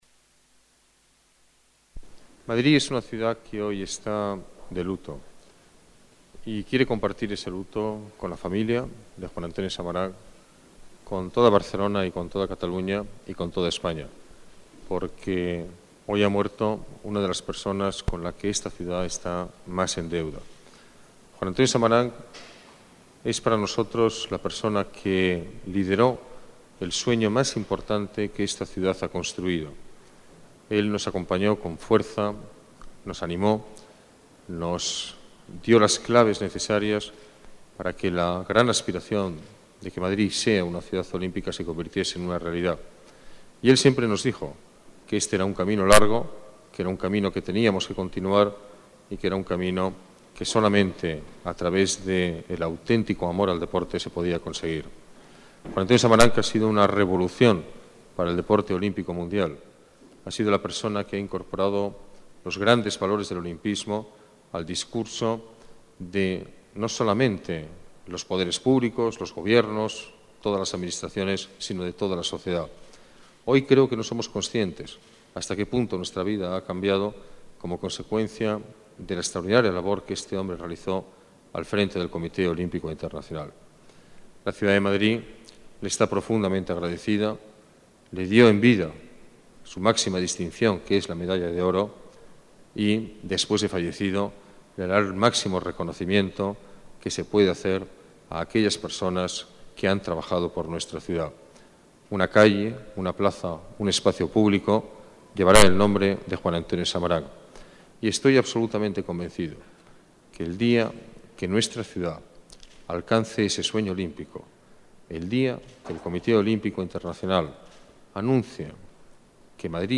Nueva ventana:Declaración del alcalde de la Ciudad de Madrid, Alberto Ruiz-Gallardón